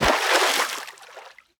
Water_splash_big_1.ogg